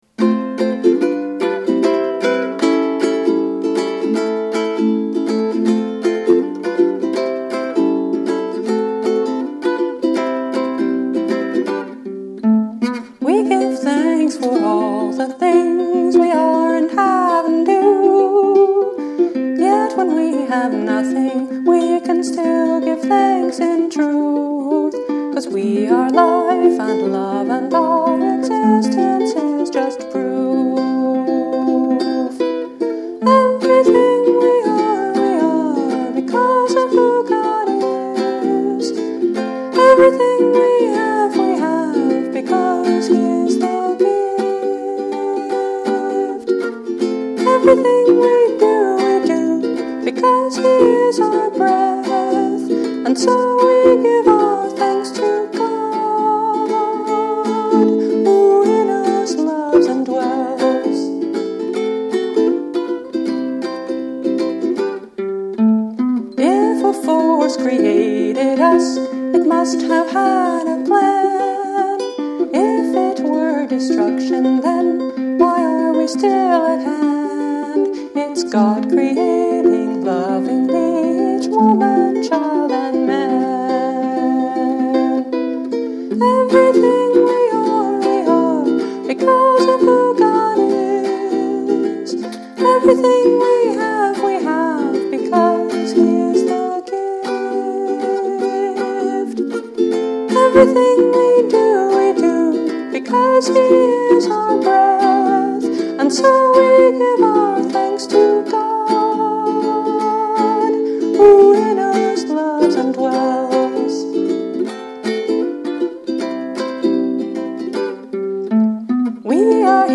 Instrument: Eventide – Mainland Mahogany Concert Ukulele